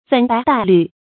粉白黛綠 注音： ㄈㄣˇ ㄅㄞˊ ㄉㄞˋ ㄌㄩˋ 讀音讀法： 意思解釋： 泛指女子的妝飾。